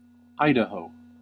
Idaho (/ˈdəh/
EYE-də-hoh) is a landlocked state in the Pacific Northwest and Mountain West subregions of the Western United States.
En-us-Idaho.ogg.mp3